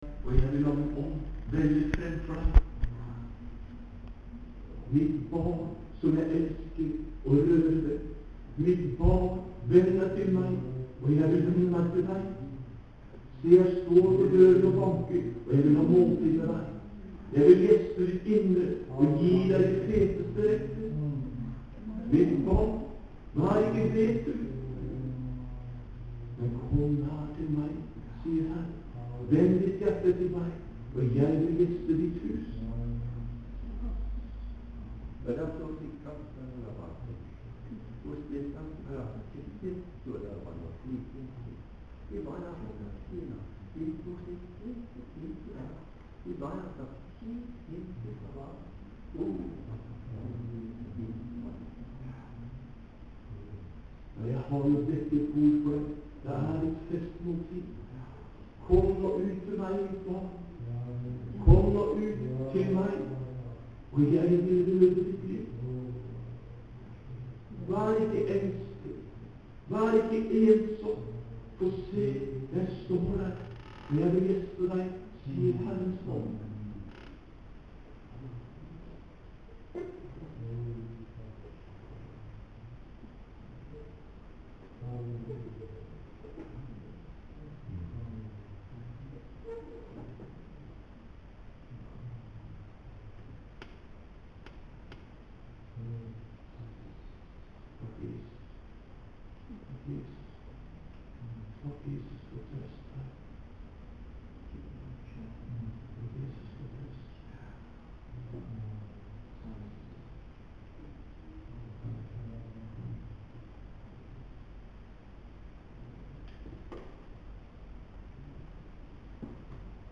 Betel Volda 24.4.08.
Av ein eller annan grunn vart der ikkje lyd på fyrste del av MD-opptaket og spelaren stoppa, men eg fekk spelt inn siste delen.